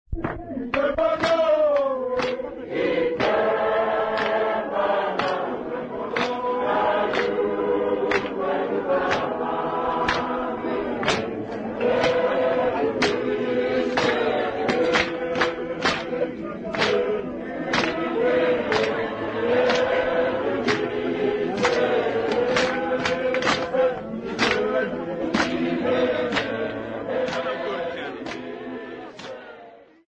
Small group at Mache Service
Folk music
Sacred music
Field recordings
Religious choral song with stamping and clapping accompaniment
96000Hz 24Bit Stereo